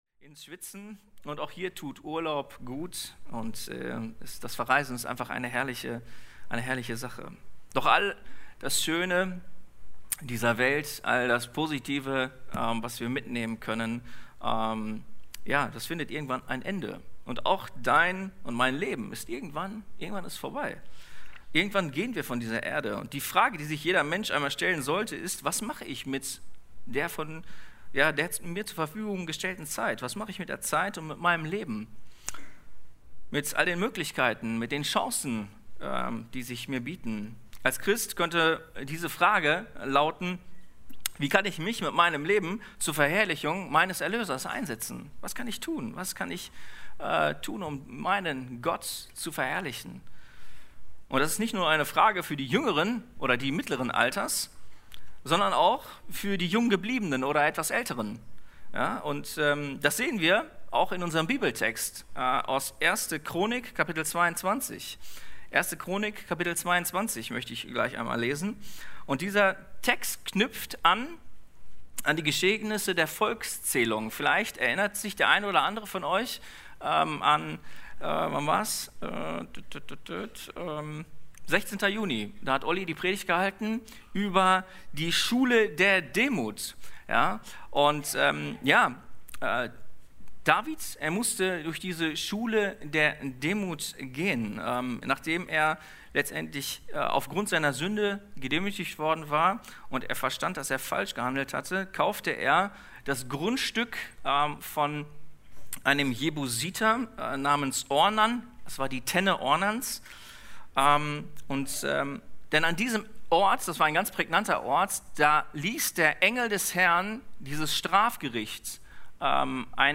Predigten – Seite 8 – Bibelgemeinde Barntrup